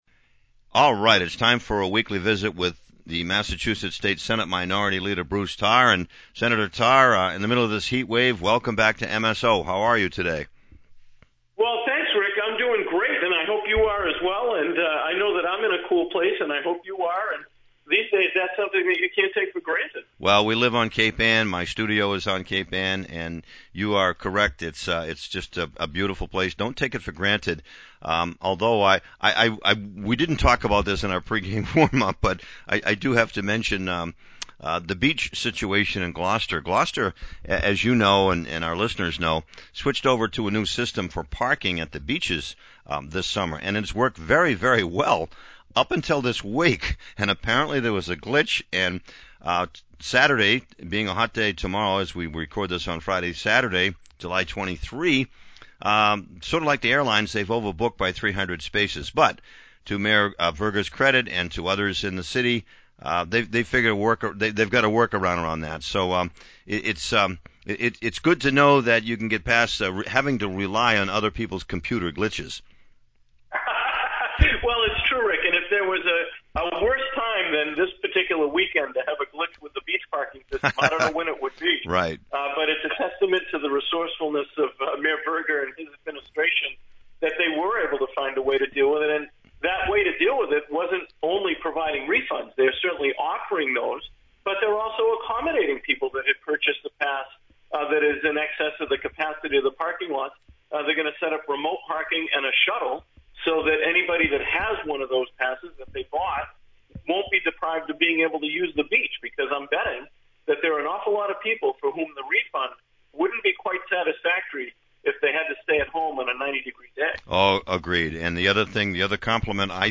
Massachusetts State Senate Minority Leader Bruce Tarr